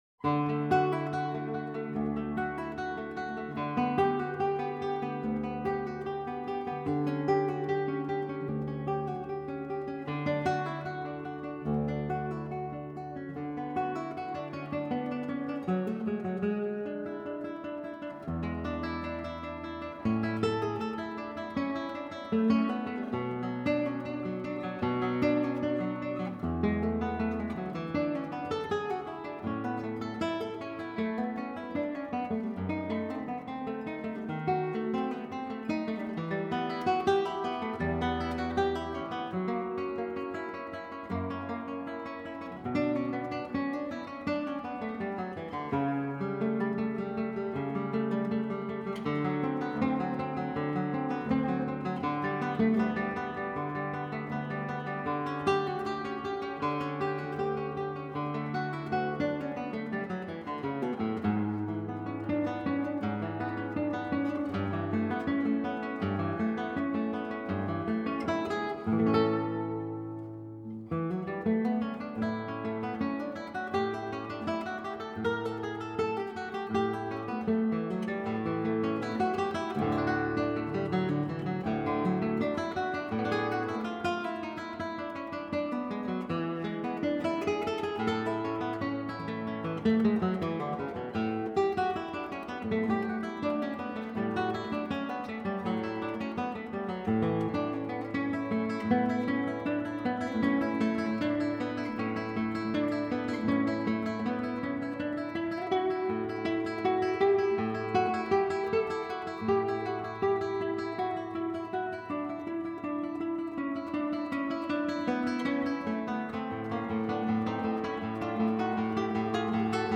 音乐类别：吉他独奏